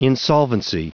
Prononciation du mot insolvency en anglais (fichier audio)
Prononciation du mot : insolvency
insolvency.wav